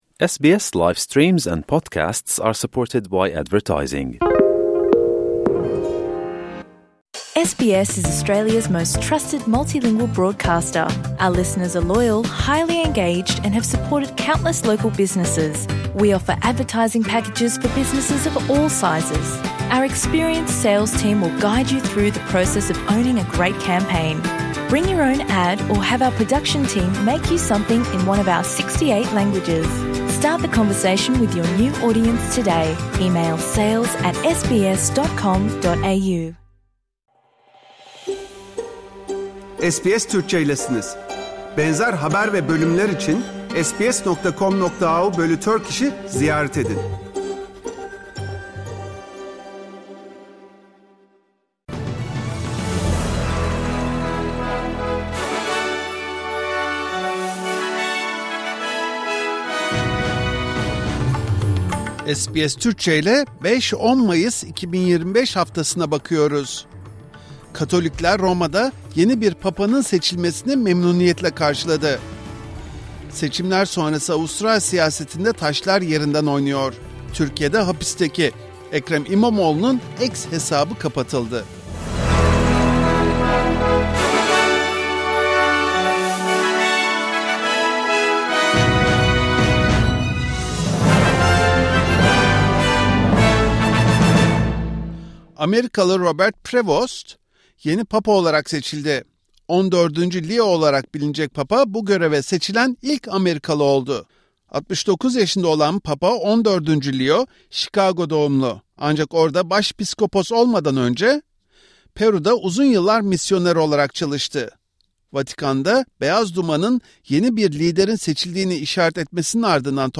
Avustralya, Türkiye ve dünyadan haftanın tüm gelişmeleri SBS Türkçe ile Haftaya Bakış bülteninde.